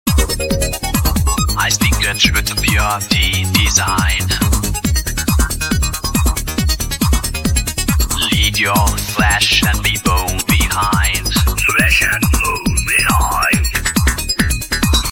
Robot Voice Music
Music by Udio